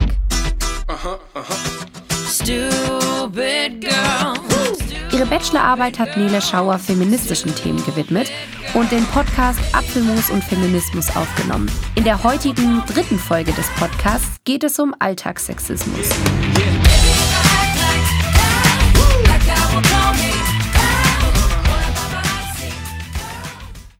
Hörstück